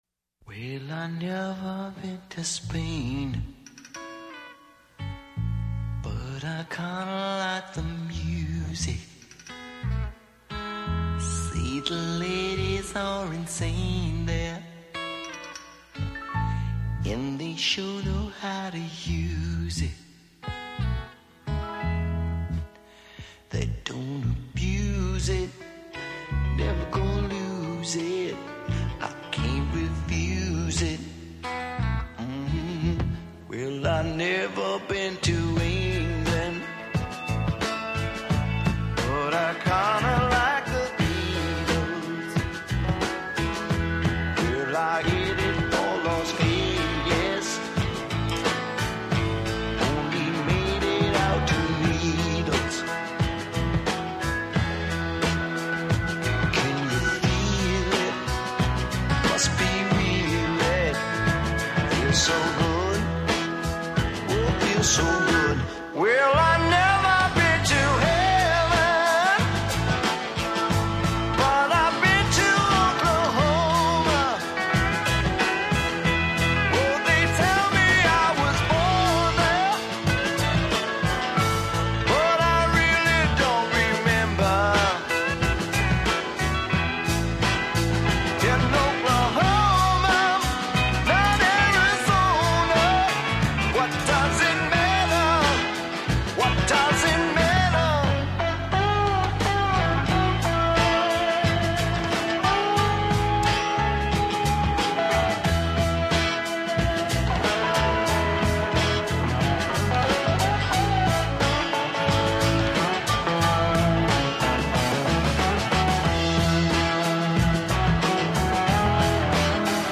Genre: Classic Pop/Classic Rock/blues